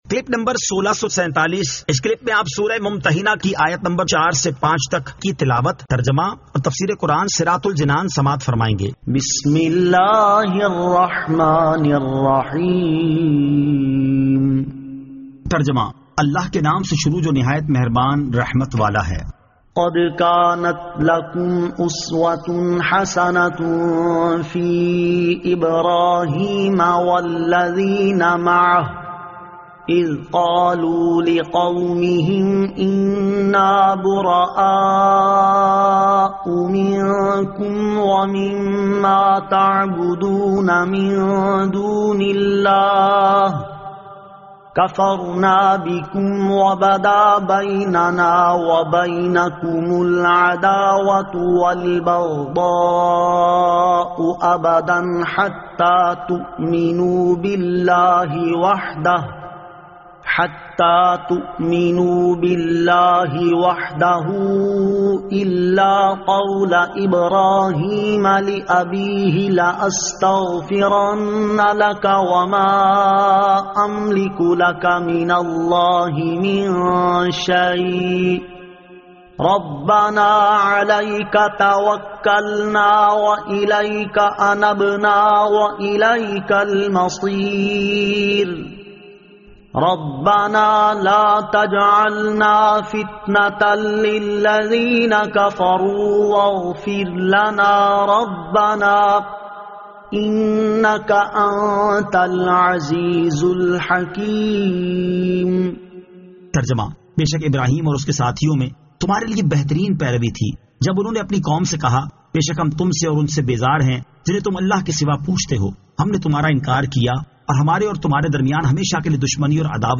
Surah Al-Mumtahinan 04 To 05 Tilawat , Tarjama , Tafseer